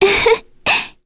Ophelia-vox-Laugh_1_kr.wav